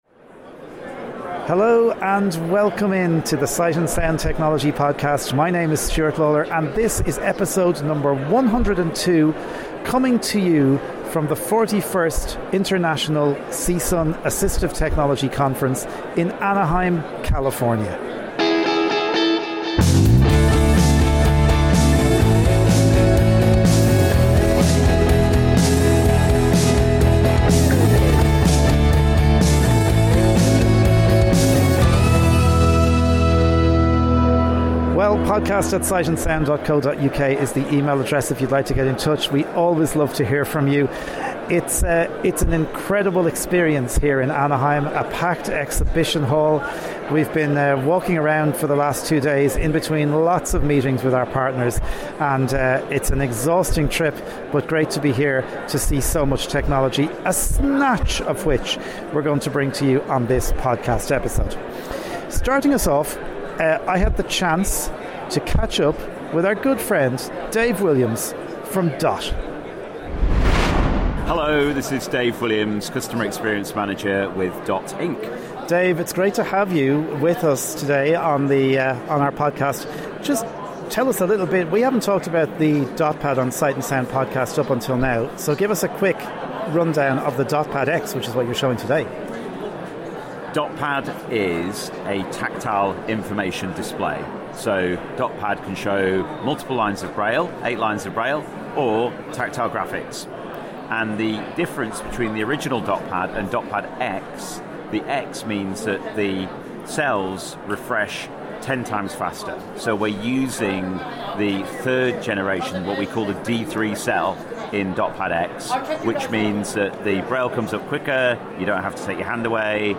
This episode comes to you from the exhibition floor of the 41st Annual CSUN Assistive Technology conference, in Anaheim, California.